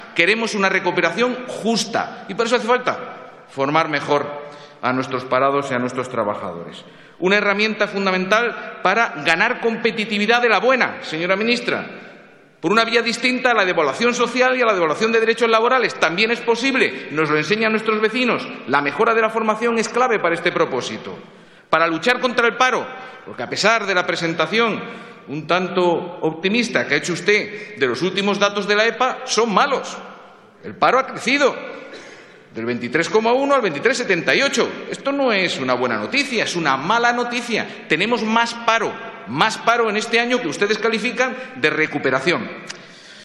Fragmento de la intervención de Rafael Simancas en el debate sobre la reforma de la formación profesional 24/04/2015. Valora la EPA conocida hoy